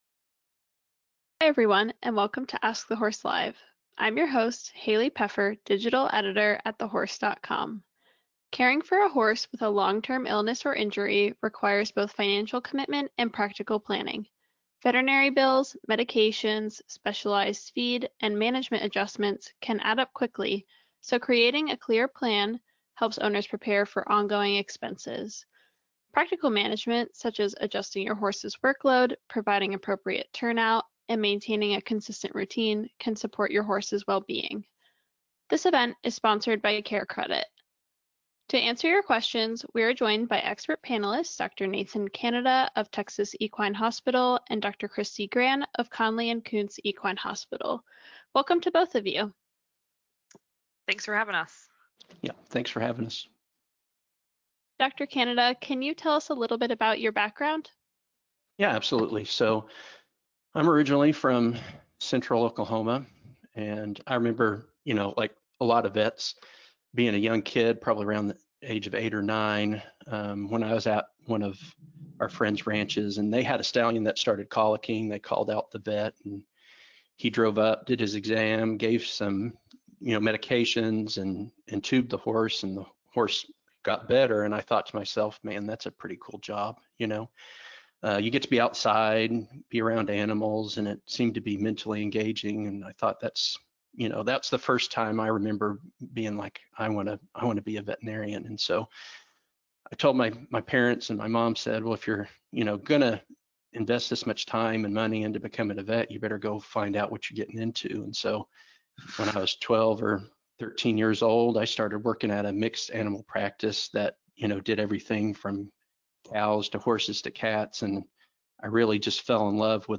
During this Ask TheHorse Live event, two veterinarians answer listener questions about managing horses with chronic illness and injury.